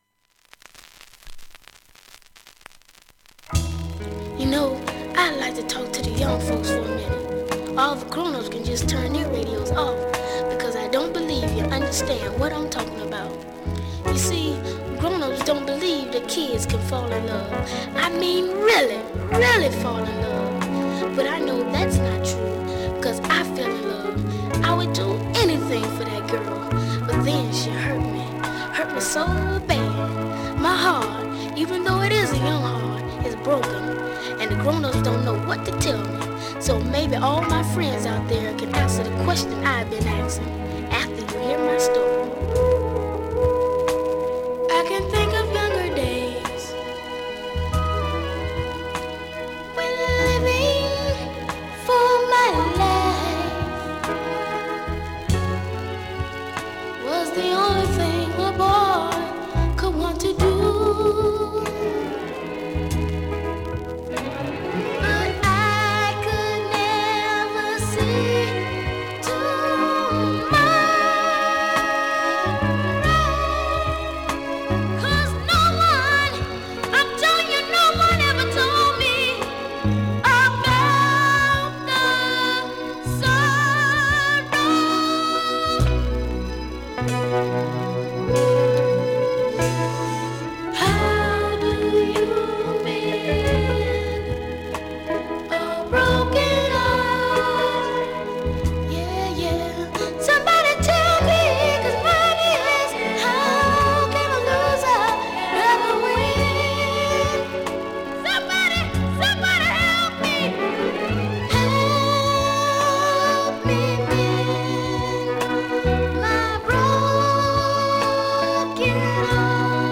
◆盤質Ａ面/EX ◆盤質Ｂ面/EXバックチリ少し入ります。